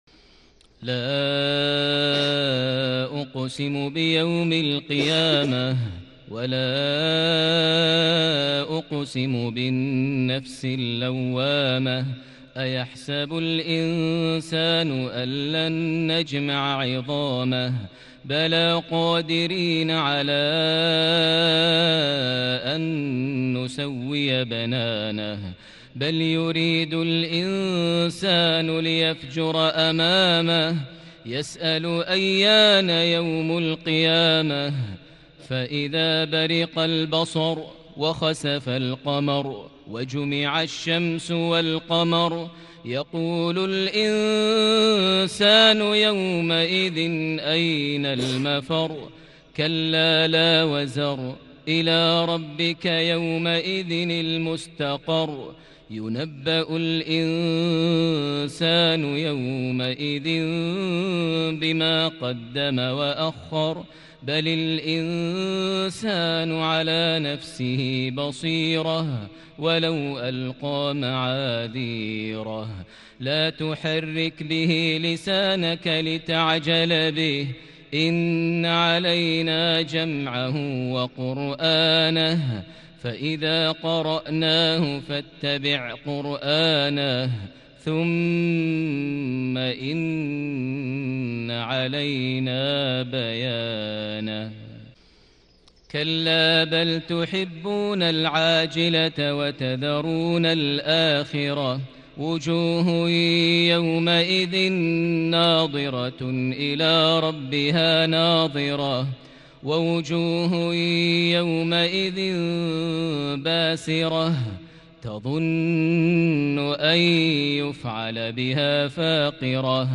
صلاة المغرب ٩ ربيع الآخر ١٤٤١هـ سورة القيامة | Maghrib prayer from Surah Al-Qiyama > 1441 🕋 > الفروض - تلاوات الحرمين